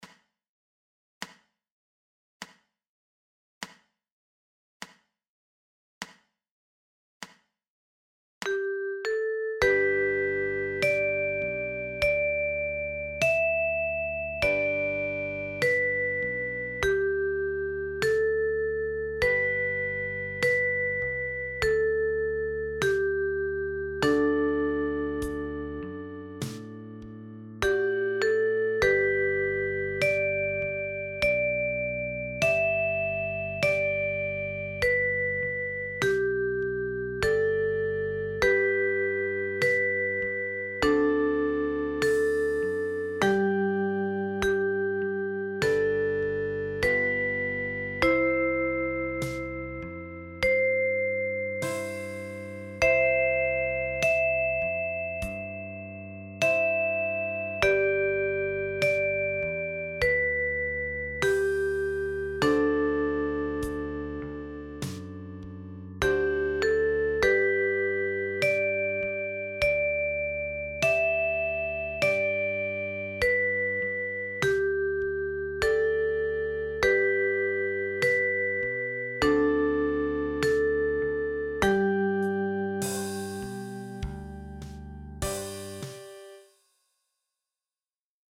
für die Mandoline